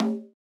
ORG Tom.wav